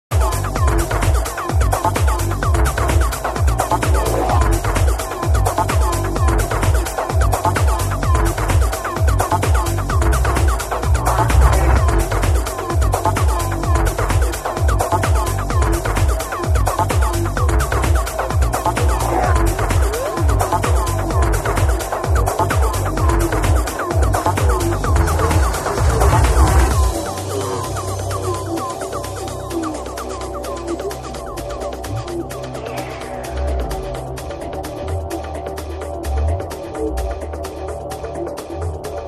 Awesome prog trance tune needs ID!